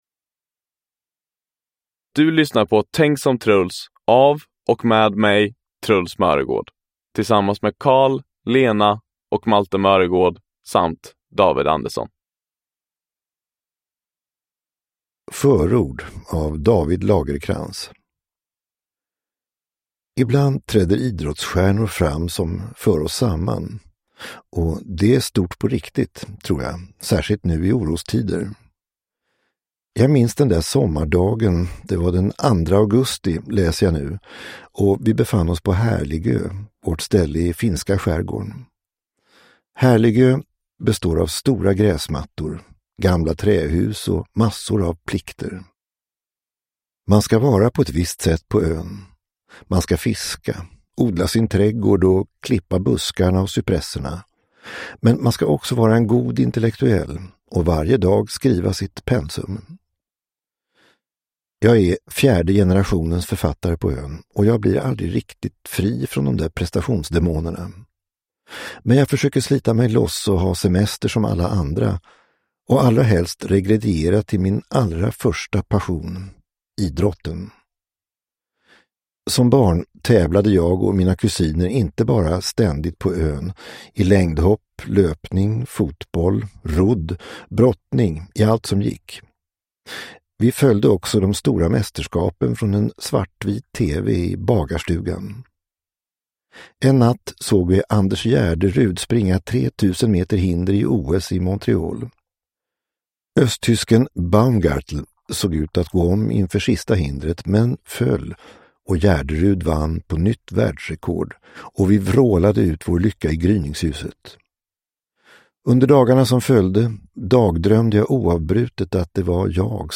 Tänk som Truls – Ljudbok
Uppläsare: Truls Möregårdh